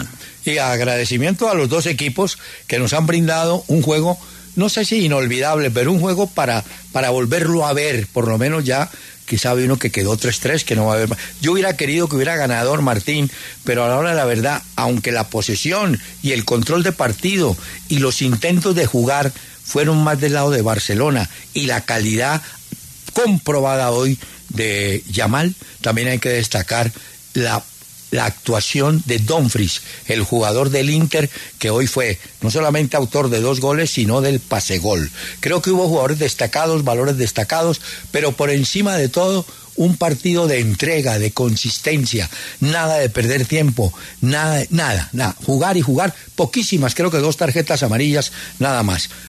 Análisis de Hernán Peláez sobre el partido de Champions entre Barcelona e Inter.
El reconocido periodista deportivo, Hernán Peláez, analizó en los micrófonos de W Radio el encuentro entre Barcelona e Inter por el duelo de ida de las semifinales de la Champions League y resaltó el juego de ambos conjuntos, siendo de total agrado para todos los aficionados al fútbol.